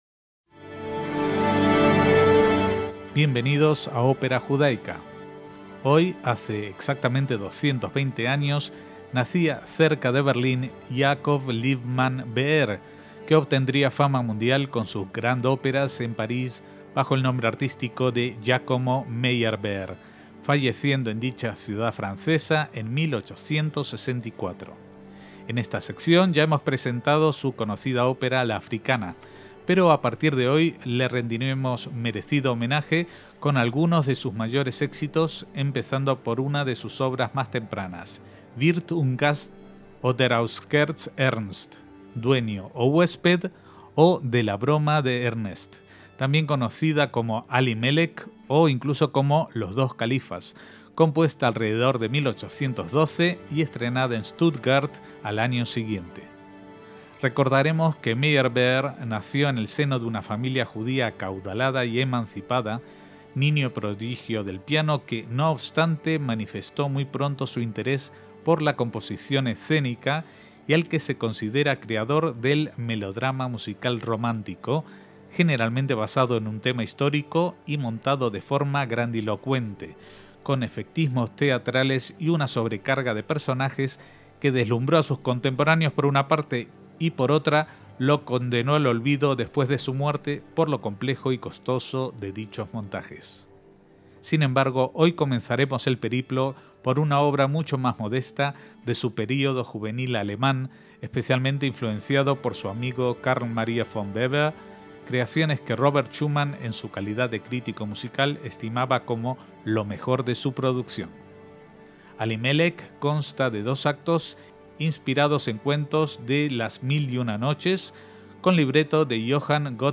ÓPERA JUDAICA